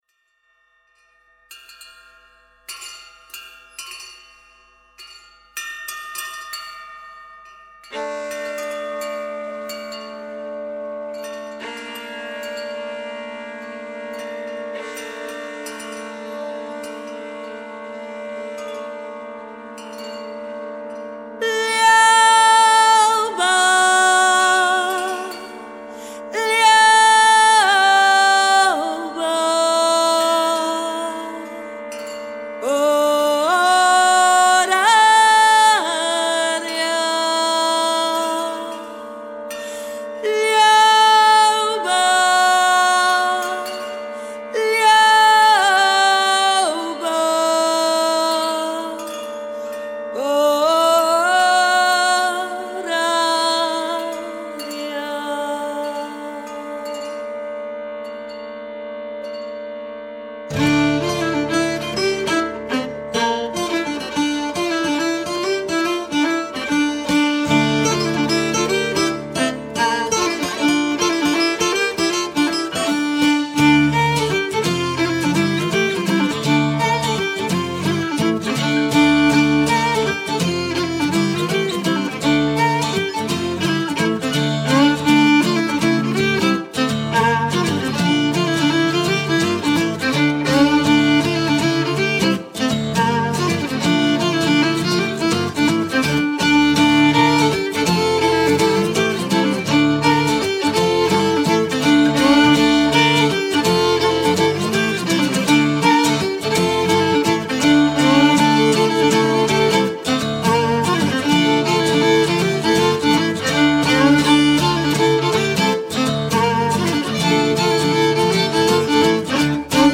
musiques traditionnelles pour le bal folk
gavotte des montagnes
Violon, voix, guimbarde, effets
Guitare, accordéon, voix, effets